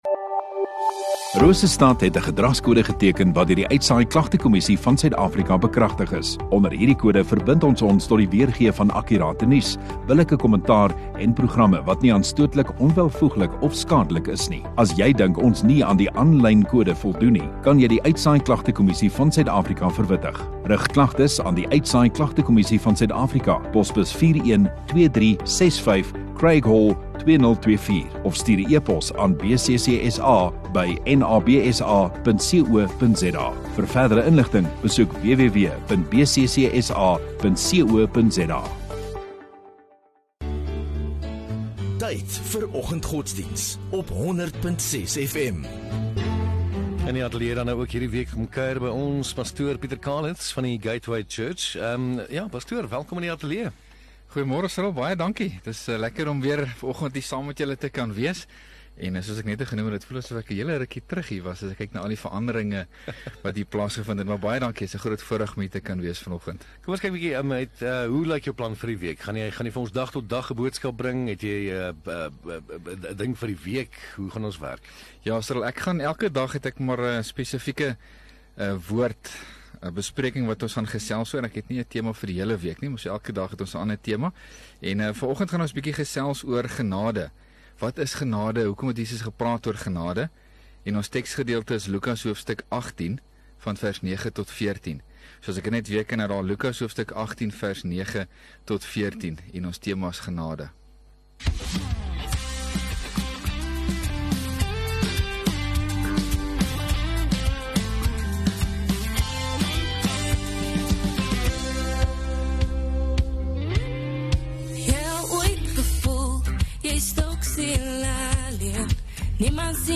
4 Mar Maandag Oggenddiens